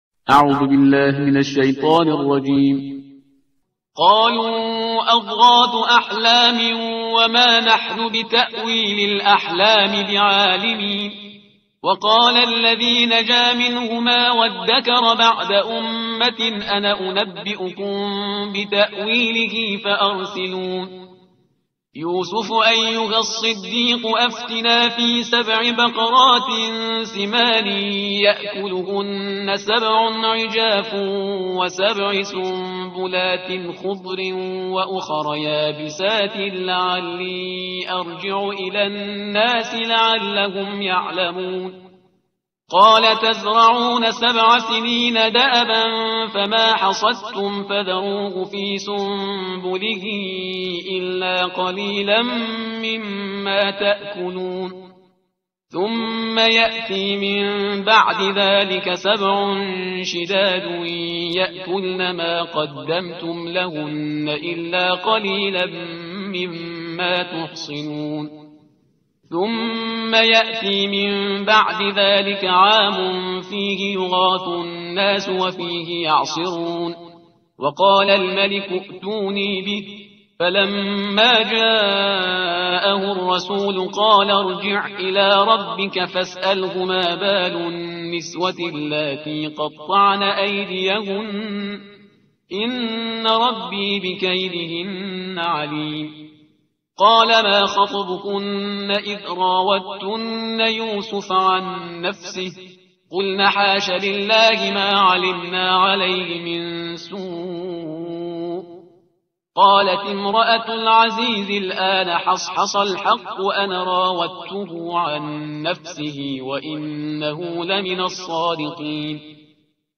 ترتیل صفحه 241 قرآن – جزء دوازدهم